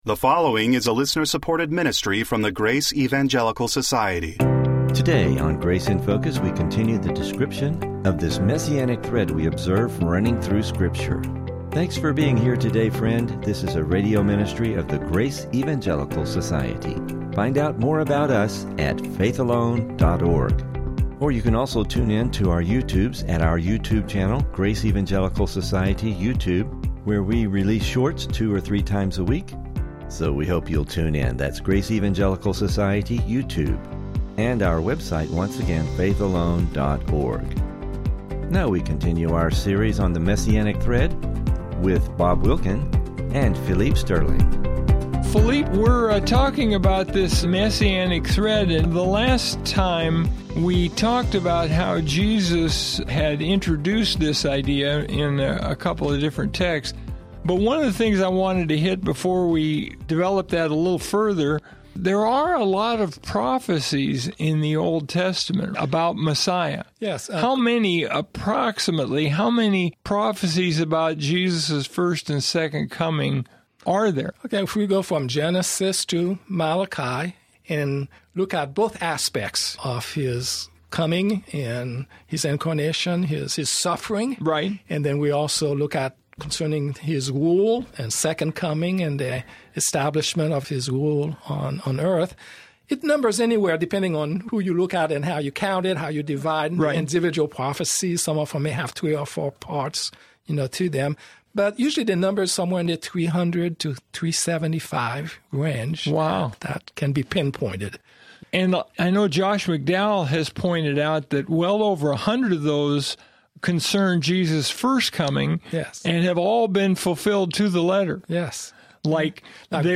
Welcome to the Grace in Focus radio.